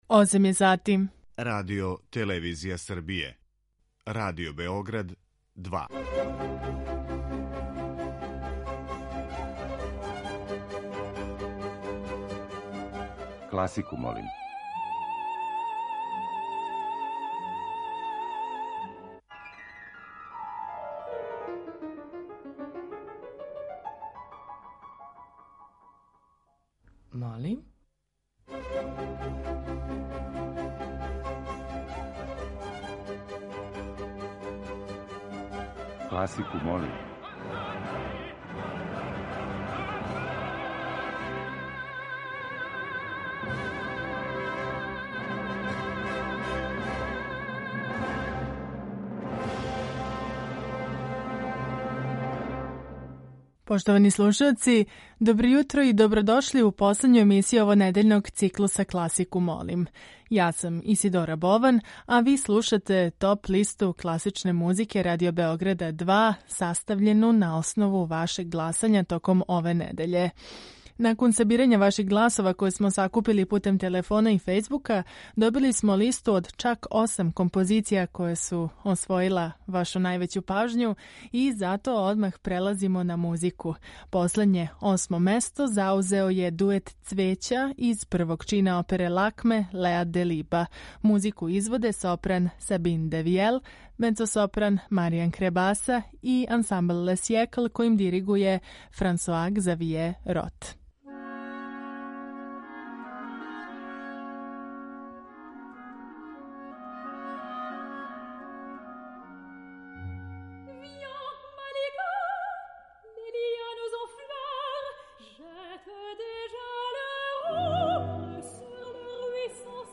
Уживо вођена емисија у којој се могу чути стилски разноврсна остварења класичне музике окренута је широком кругу слушалаца.